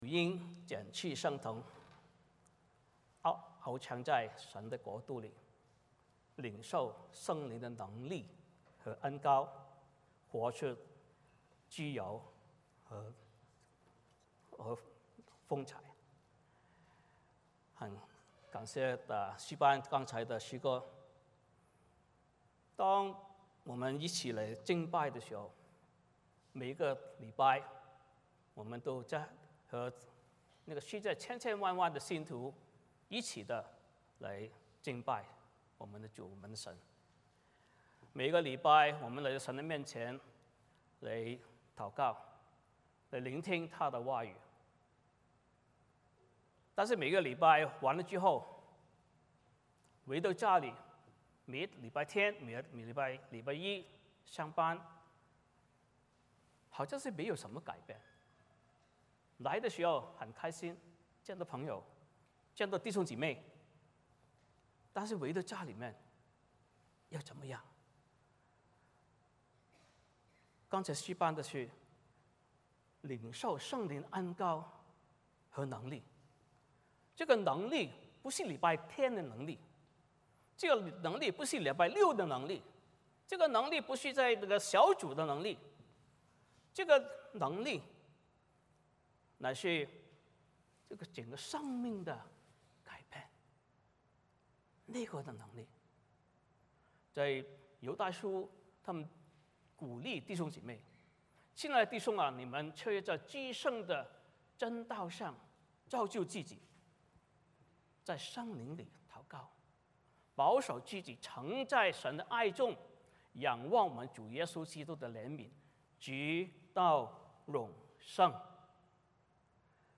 欢迎大家加入我们国语主日崇拜。
1-16 Service Type: 主日崇拜 欢迎大家加入我们国语主日崇拜。